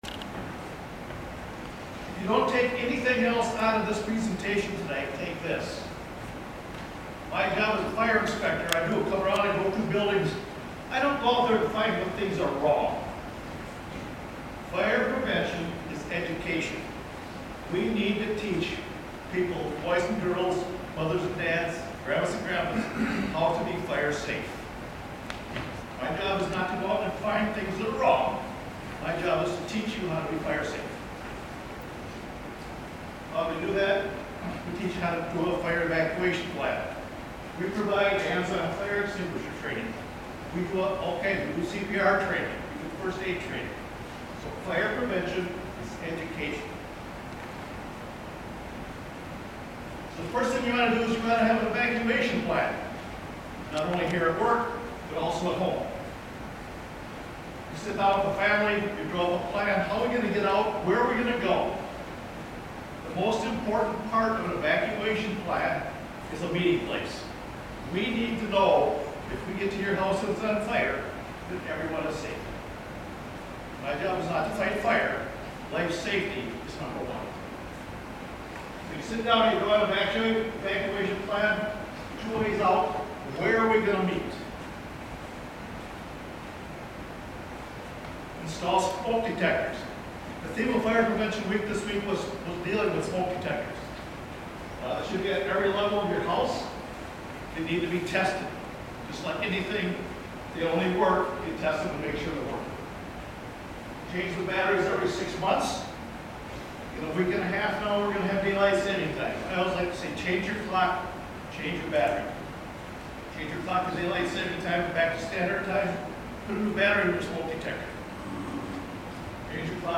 gives fire safety tips Oct. 28, 2024, during the installation's Safety and Occupational Health Council meeting at Fort McCoy, Wis.